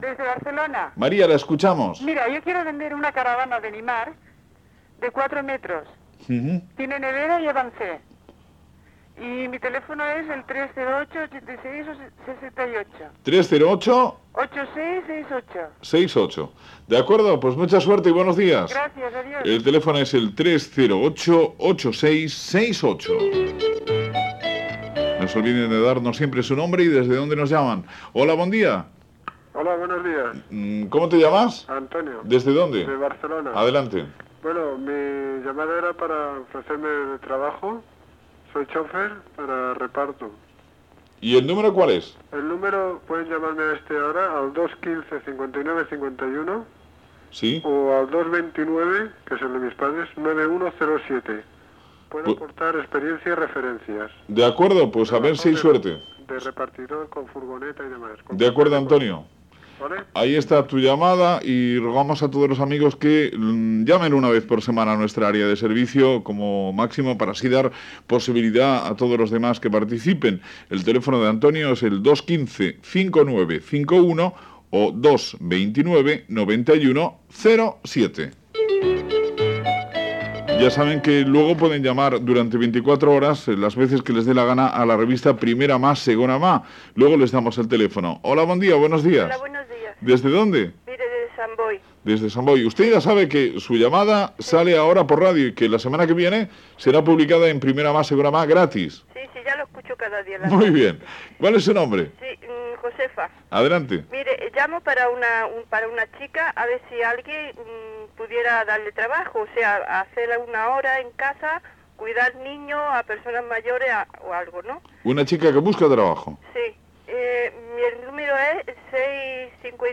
Secció "Area de servicio", amb la participació dels oients que compren, venen, intercanvien o fan ofertes laborlas